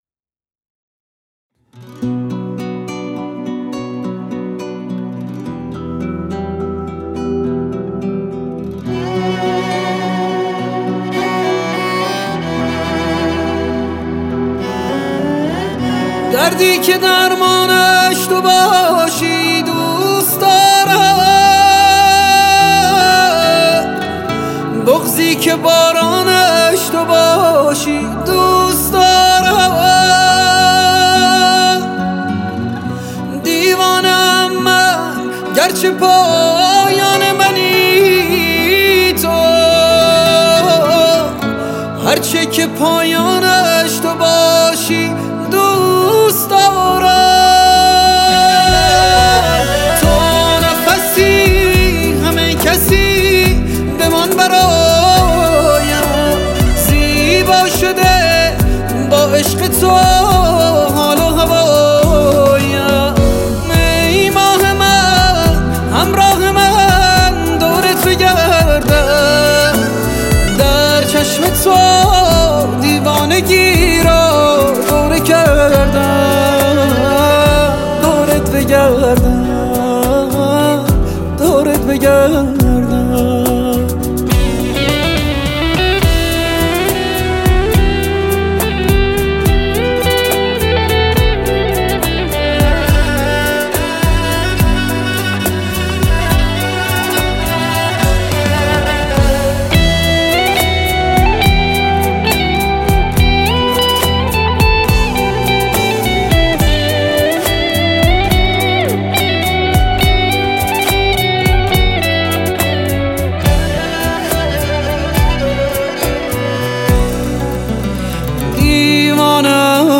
• دسته آهنگ پاپ